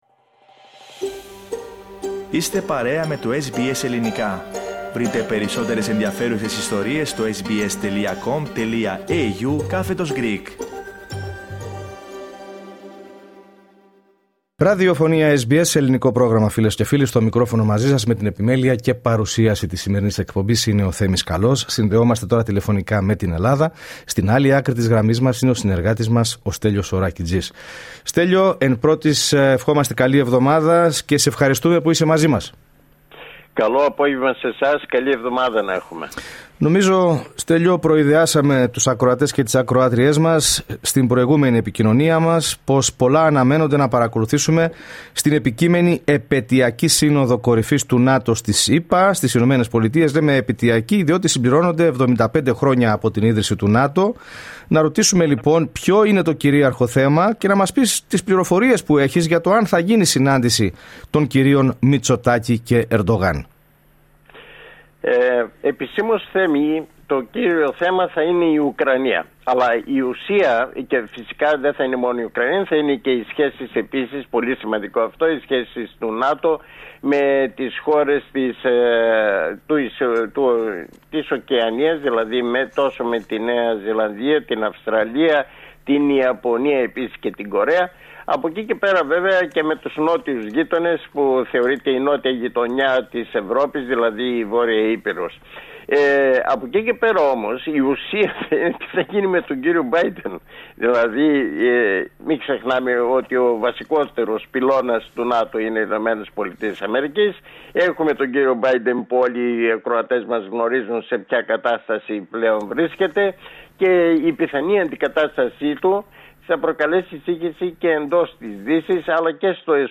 Ακούστε την εβδομαδιαία ανταπόκριση από Ελλάδα